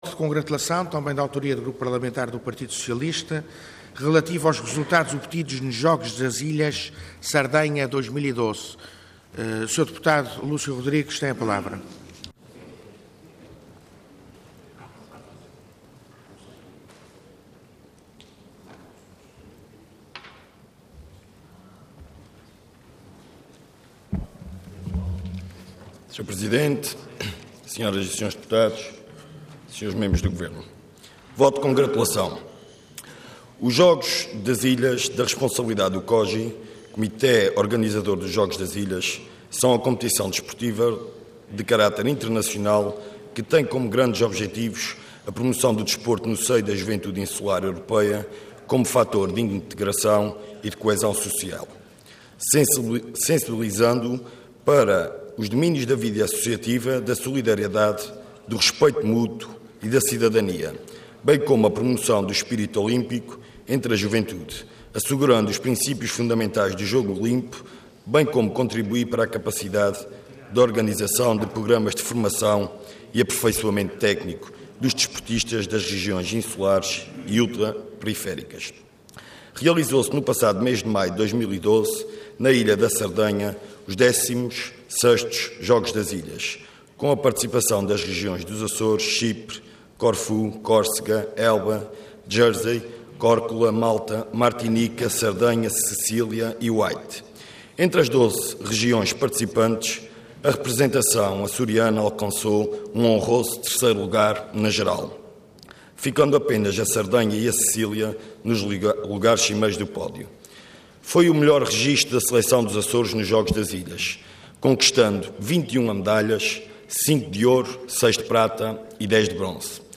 Intervenção Voto de Congratulação Orador Lúcio Rodrigues Cargo Deputado Entidade PS